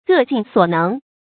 各盡所能 注音： ㄍㄜˋ ㄐㄧㄣˋ ㄙㄨㄛˇ ㄣㄥˊ 讀音讀法： 意思解釋： 各人盡自己的能力去做。